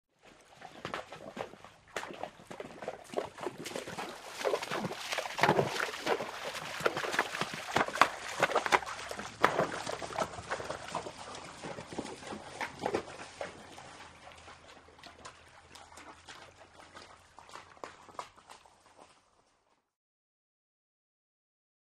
Horse Through Deep Water; Single Horse On Wet Rocks And Into Deep Water Sloshing In And By From Right To Left And Away. Medium.